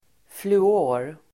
Ladda ner uttalet
Folkets service: fluor fluor substantiv, fluorine Uttal: [flu'å:r] Böjningar: fluoren Definition: ett kemiskt grundämne som bl a används för att motverka karies Sammansättningar: fluor|sköljning (fluoride rinse)